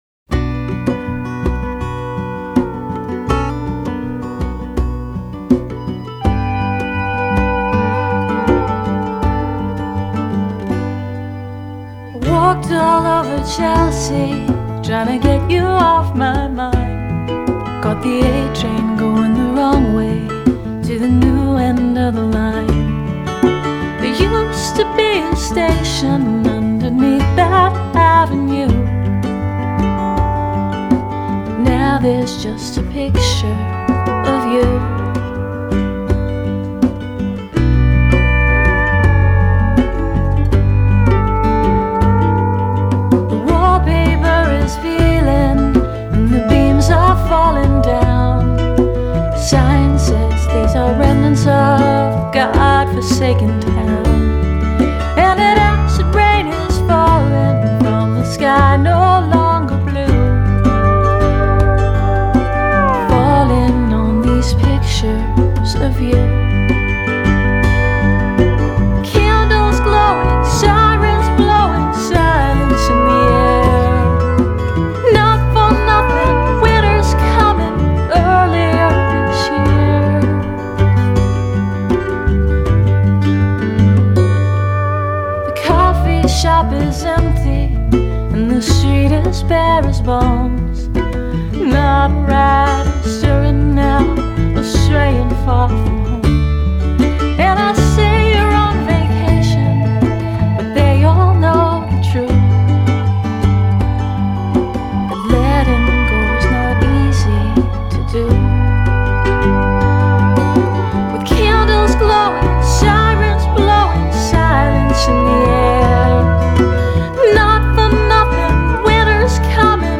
Folk/Roots